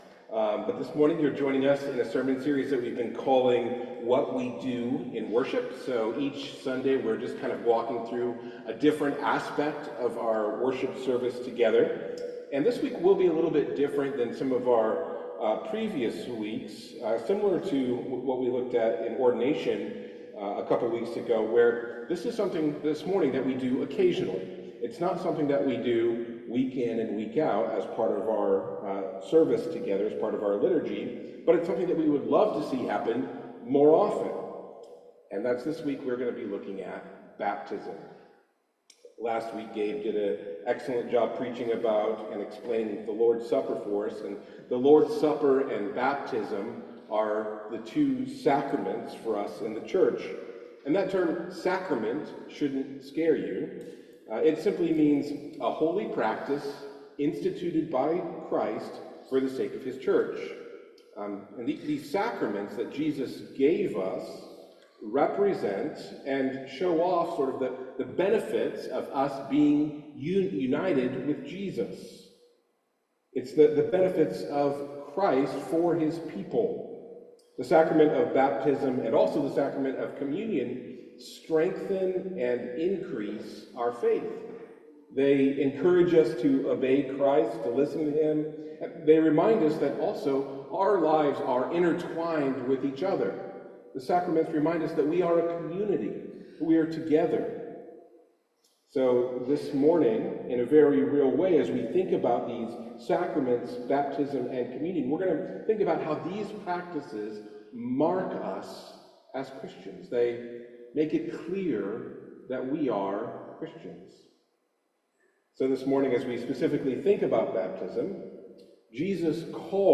The sermon highlights baptism as a public proclamation of faith and a symbol of God’s powerful work in salvation. Ultimately, baptism is less about our response to God and more about what God is doing in and through us.